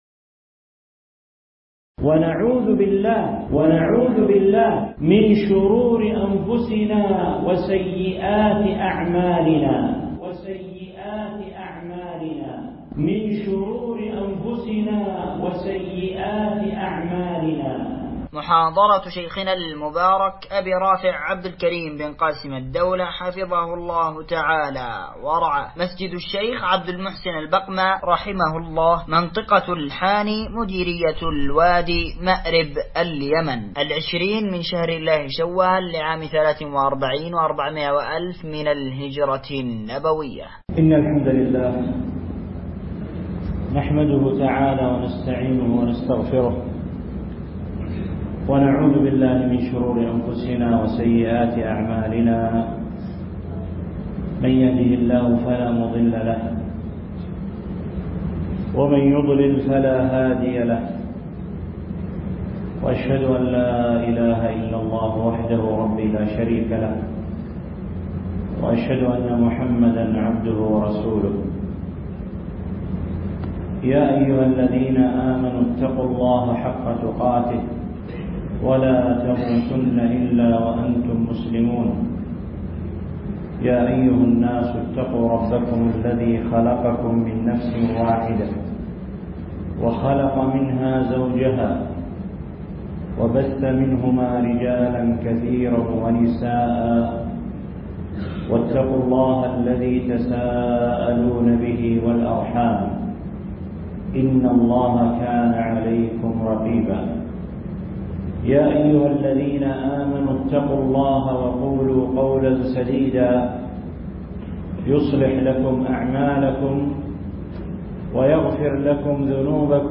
نعوذ بالله من شرور أنفسنا وسيئات أعمالنا محاضرة ← شيخنا المبارك